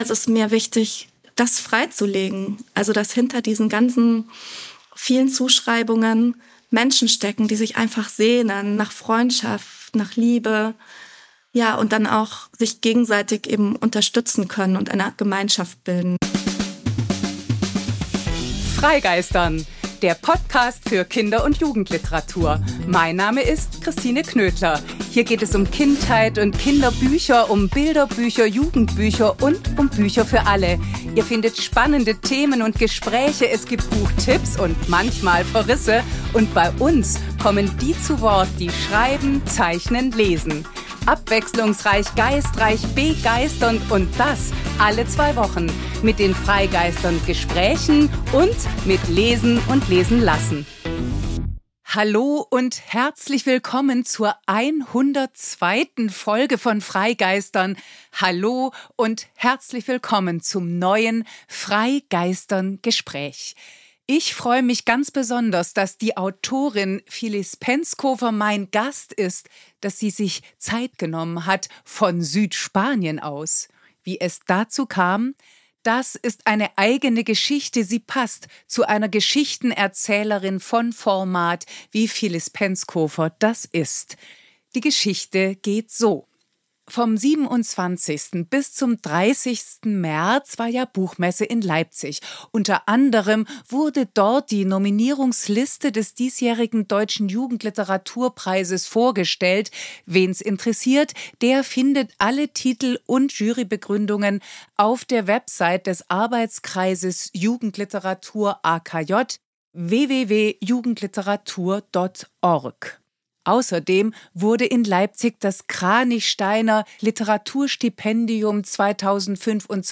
Ein Gespräch über die Dringlichkeit von Geschichten, die Angst der Gesellschaft, den Mut beim Schreiben, Widerstände und Weitermachen, famose Figuren, das Spiel mit Perspektiven, befreiendes Lachen und die Erkenntnis, die darin liegen kann.